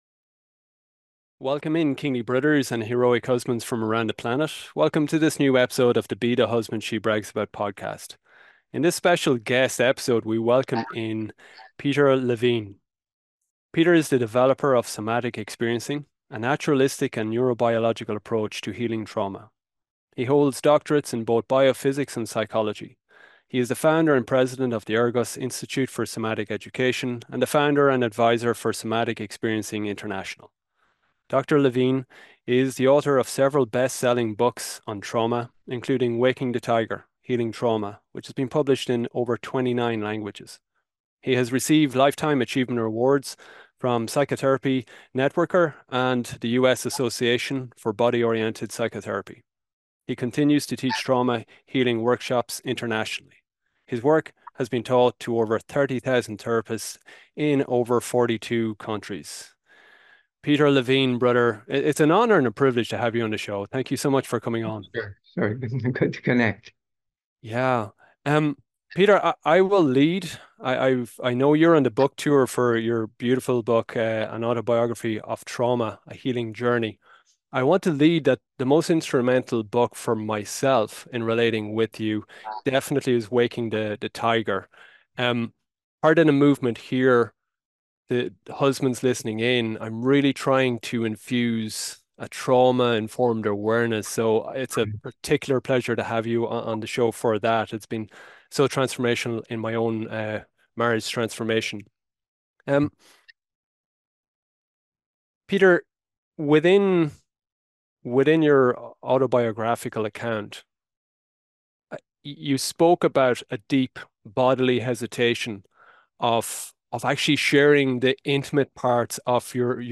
You meet the brilliant Peter Levine in this special guest episode.